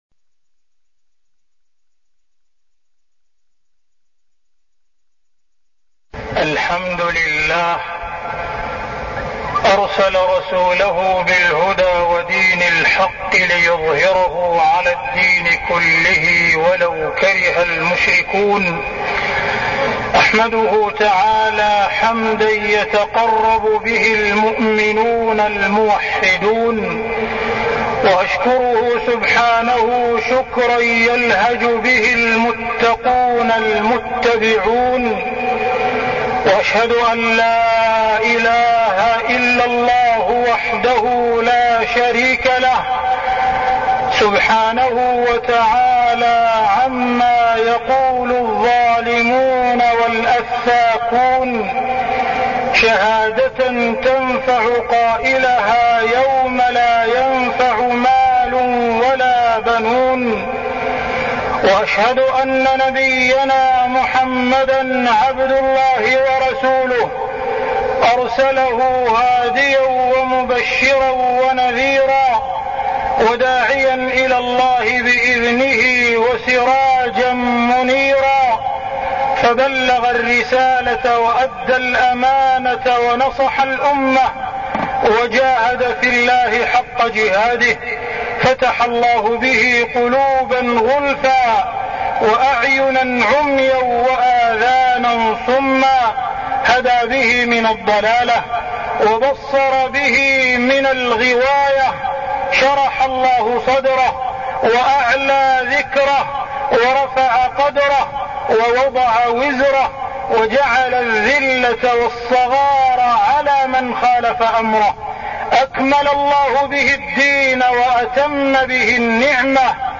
تاريخ النشر ١٤ ربيع الأول ١٤١٣ هـ المكان: المسجد الحرام الشيخ: معالي الشيخ أ.د. عبدالرحمن بن عبدالعزيز السديس معالي الشيخ أ.د. عبدالرحمن بن عبدالعزيز السديس اتباع السنة واجتناب البدع